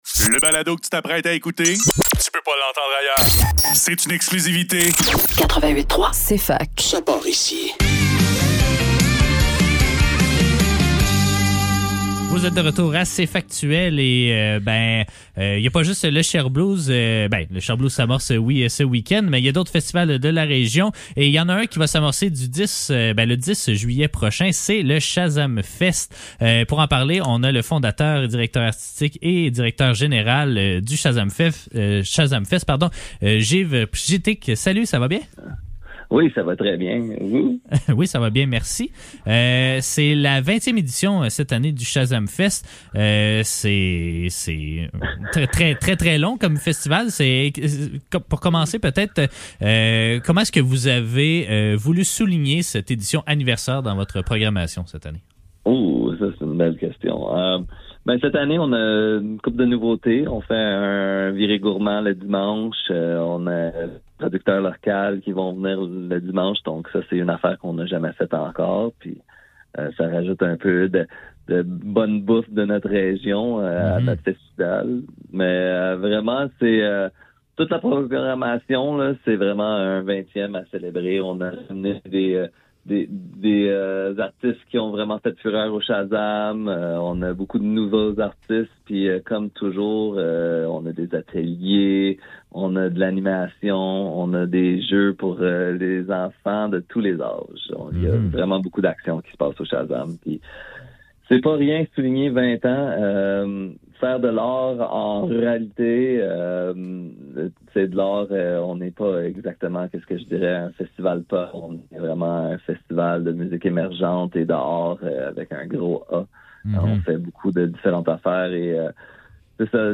Cfaktuel Cfaktuel - Entrevue : Shazamfest - 03 Juillet 2025 Jul 04 2025 | 00:12:51 1x 00:00 / 00:12:51 Subscribe Share RSS Feed Share Link Embed